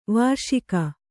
♪ vārṣika